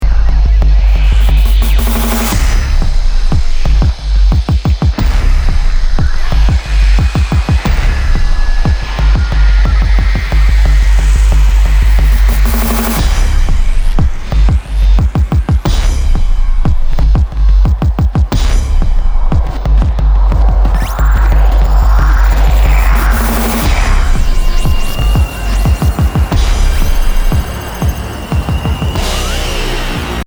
Sounds, rhythmic noise, and atmospheric constructions.
This is an instrumental disc with 1 spoken word track.